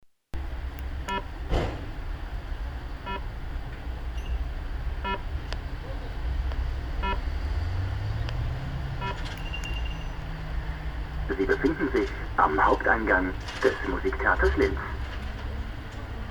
Das hat den Vorteil, dass man mit Hilfe der Akustik an ein bestimmtes Ziel geleitet wird.
Hörbeispiel akustische Auffindungsboje Musiktheater Linz: